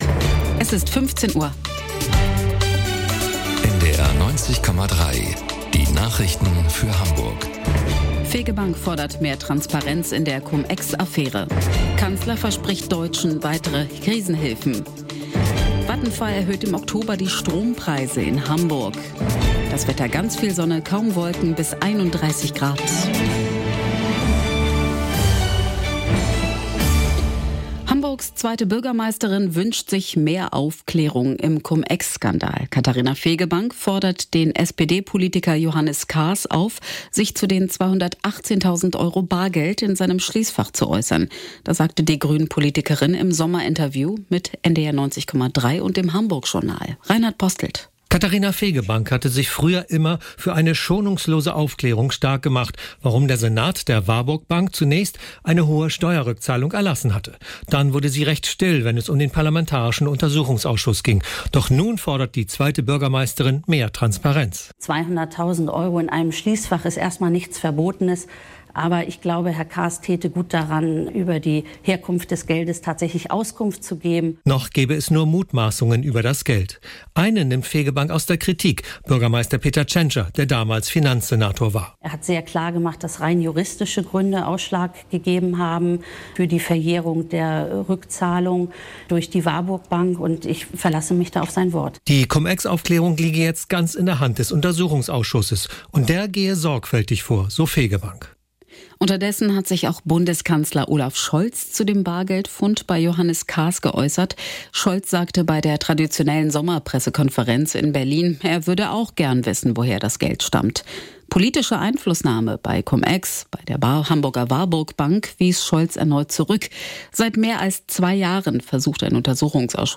Nachrichten - 19.11.2022